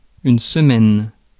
Ääntäminen
Ääntäminen France: IPA: [sə.mɛn] Tuntematon aksentti: IPA: /smɛn/ Haettu sana löytyi näillä lähdekielillä: ranska Käännös Konteksti Ääninäyte Substantiivit 1. week US UK 2. septet 3. sennight vanhahtava Suku: f .